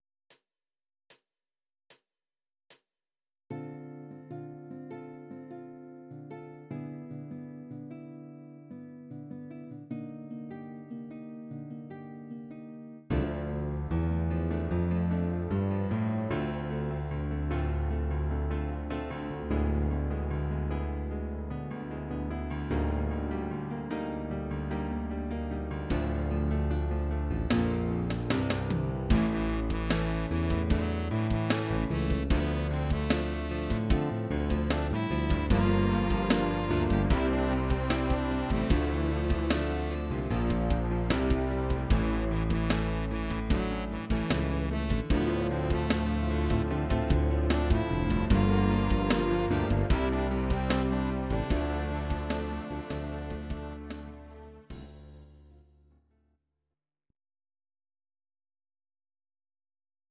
Audio Recordings based on Midi-files
Our Suggestions, Pop, Rock, 1980s